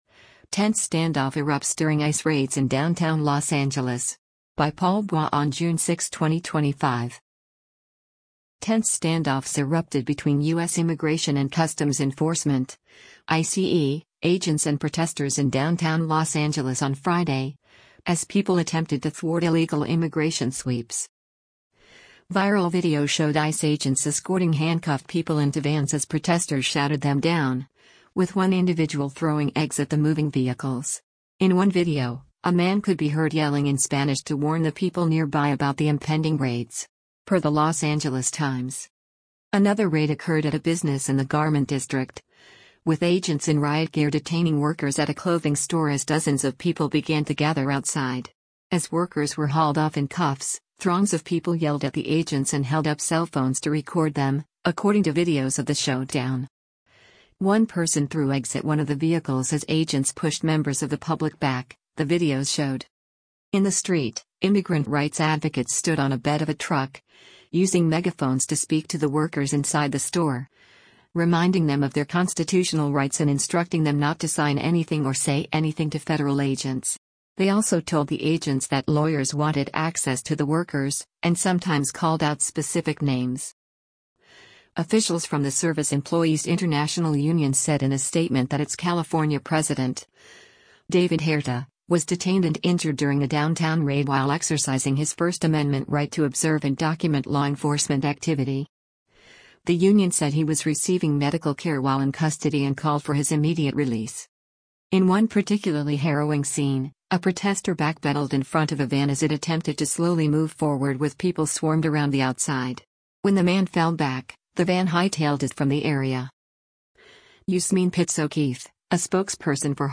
Viral video showed ICE agents escorting handcuffed people into vans as protesters shouted them down, with one individual throwing eggs at the moving vehicles. In one video, a man could be heard yelling in Spanish to warn the people nearby about the impending raids.